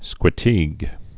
(skwĭ-tēg)